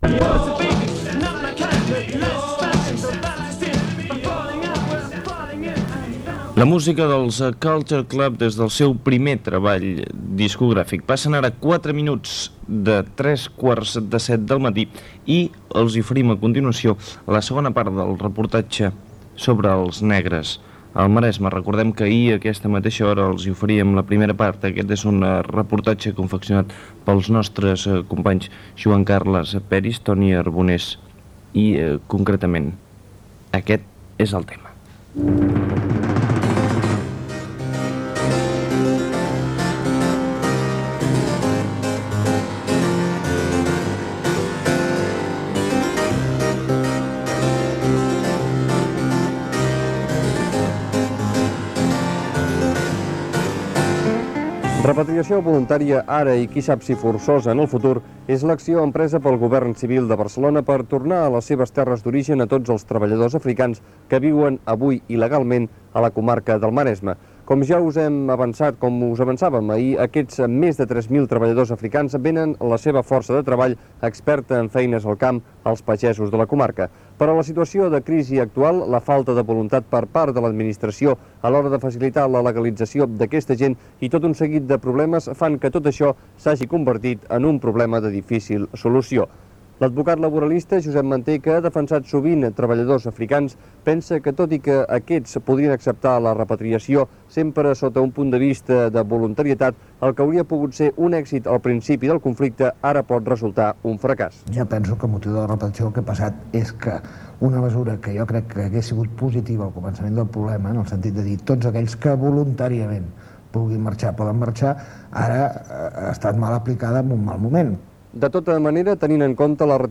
Tema musical, hora, segona part del reportatge sobre la situació dels treballadors negres del Maresme, tema musical,
Info-entreteniment